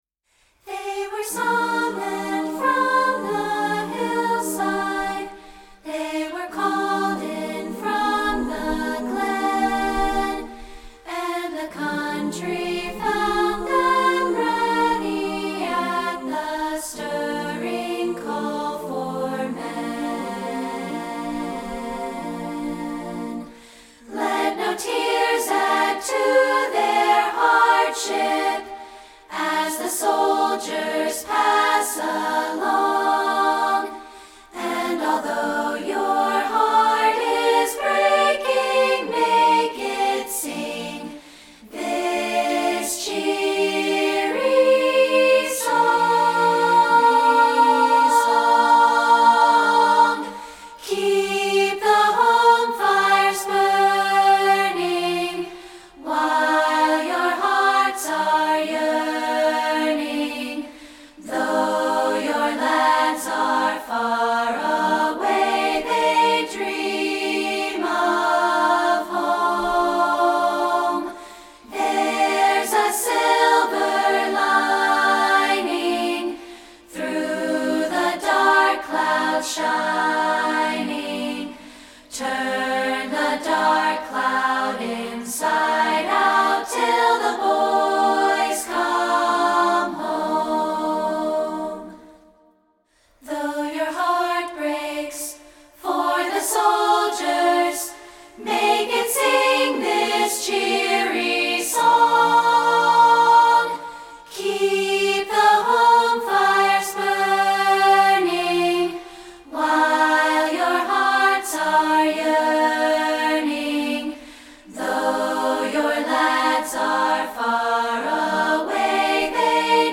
A Cappella Version
This is an a cappella version.